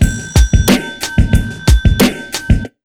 Index of /90_sSampleCDs/USB Soundscan vol.02 - Underground Hip Hop [AKAI] 1CD/Partition A/11-91BEATMIX